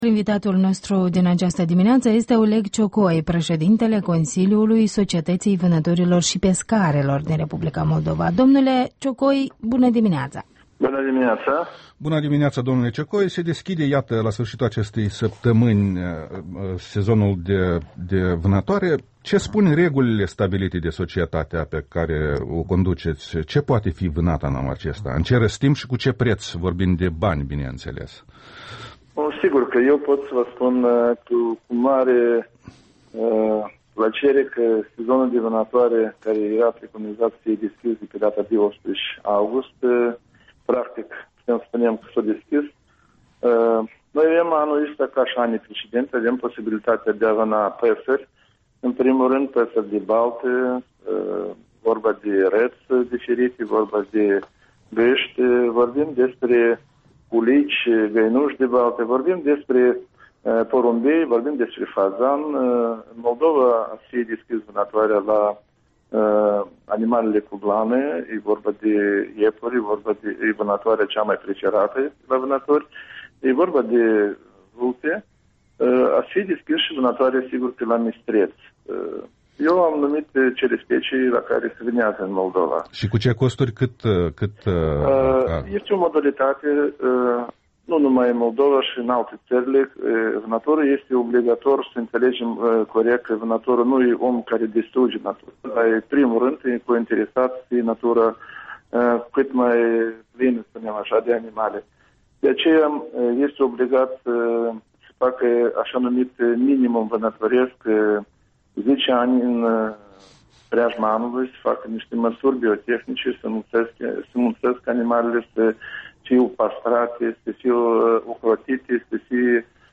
Interviul dimineții la REL